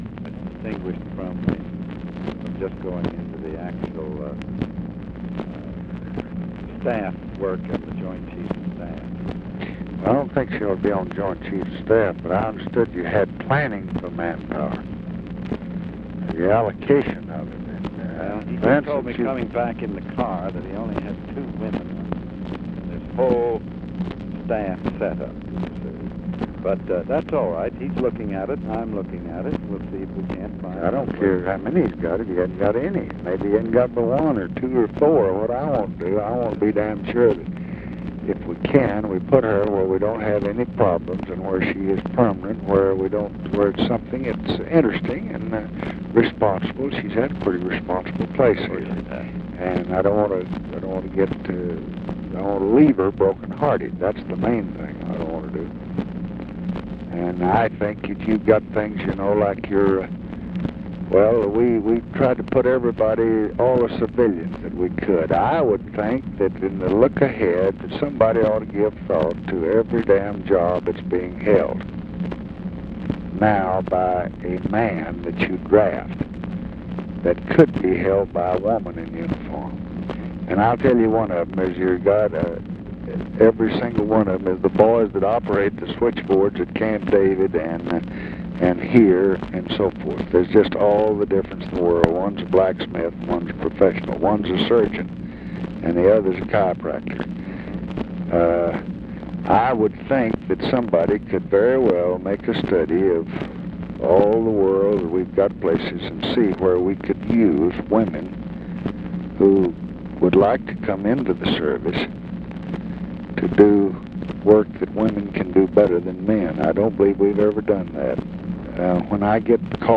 Conversation with CLARK CLIFFORD, October 22, 1968
Secret White House Tapes | Lyndon B. Johnson Presidency